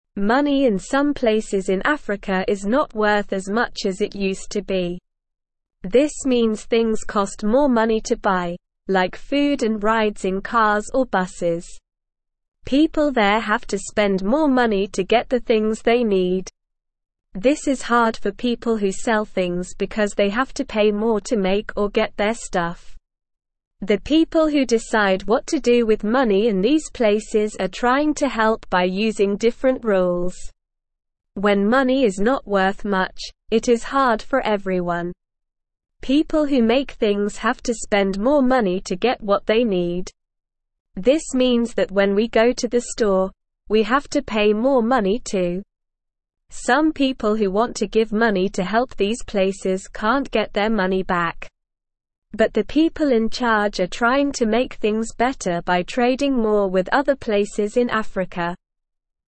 Slow
English-Newsroom-Beginner-SLOW-Reading-Money-Worth-Less-in-Africa-People-Struggling-to-Buy.mp3